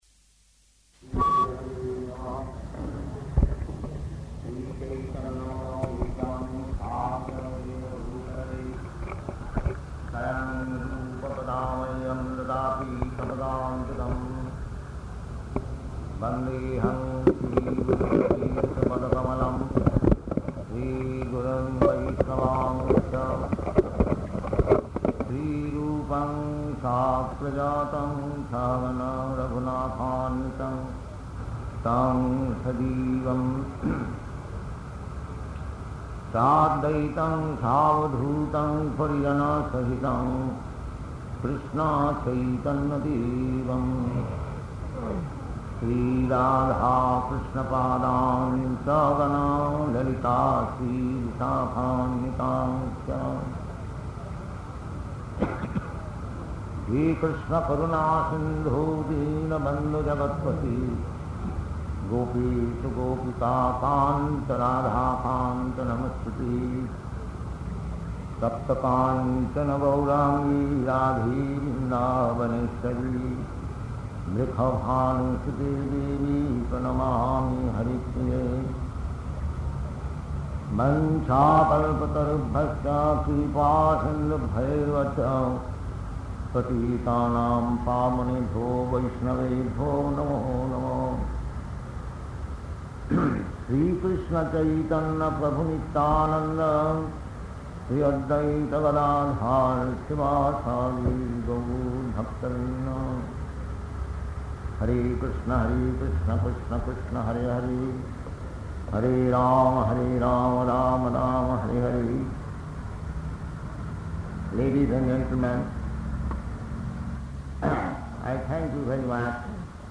Type: Bhagavad-gita
Location: Bombay